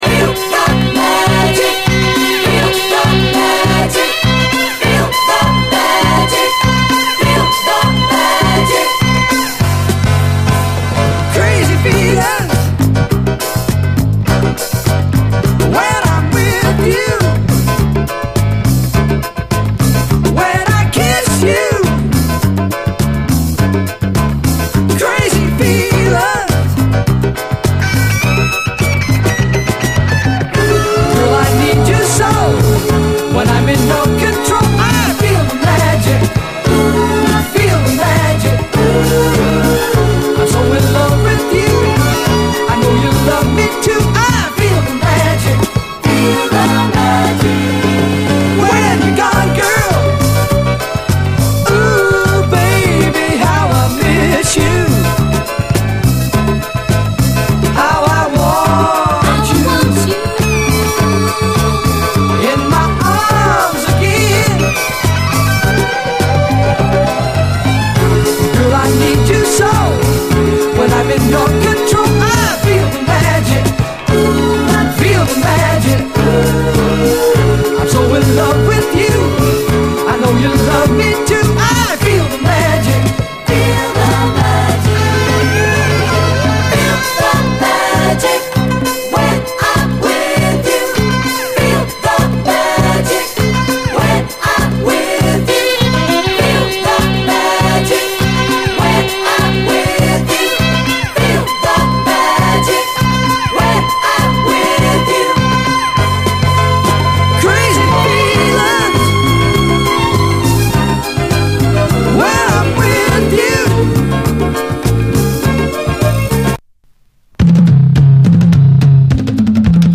SOUL, 70's～ SOUL, DISCO, SSW / AOR
白人シンガーの隠れたモダン・ソウル盤！
甘いコーラスとピアノ、ロッキンなギターのサマー・フィール！